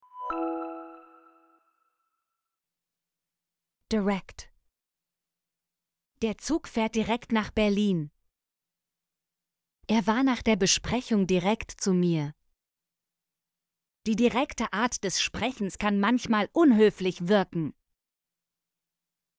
direkt - Example Sentences & Pronunciation, German Frequency List